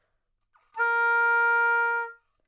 双簧管单音 " 双簧管 A4
描述：在巴塞罗那Universitat Pompeu Fabra音乐技术集团的goodsounds.org项目的背景下录制。单音乐器声音的Goodsound数据集。
标签： 好声音 单注 多样本 Asharp4 纽曼-U87 双簧管
声道立体声